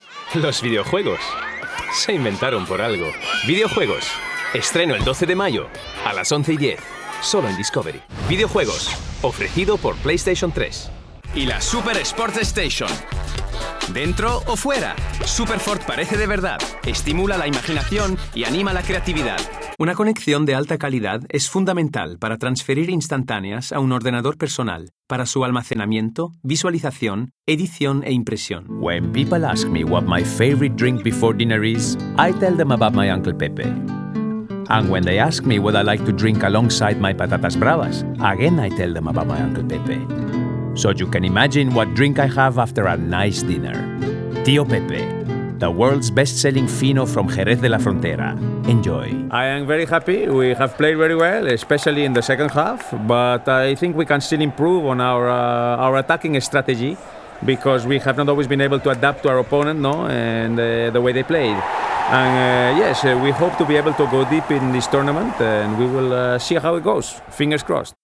Character Reel
Playing age: 30 - 40sNative Accent: French, German, International, SpanishOther Accents: French, German, Italian, Russian, Spanish
He has a versatile voice ranging from a reassuring warm baritone to youthful high-energy delivery and has an exceptional ear for accents and character voices.
He has a professionally equipped home studio for remote recording.